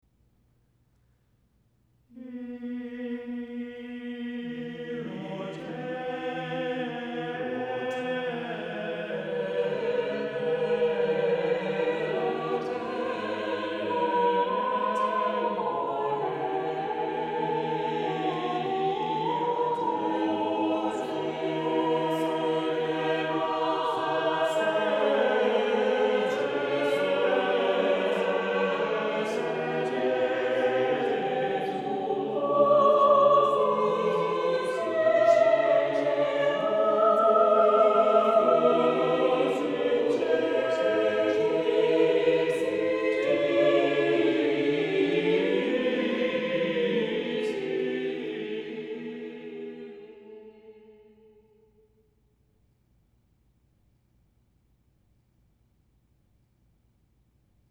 • Music Type: Choral
• Voicing: SSATB
• Accompaniment: a cappella